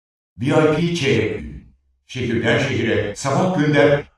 Similar to the first example, we now use a better quality speech signal and add reverb synthetically and trying to estimate the original form.
The reverb is seemed to end unnatural. This is because RIR (samples close to 0) is cropped in order to reduce the computational time.